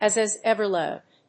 アクセントas…as éver líved [wás]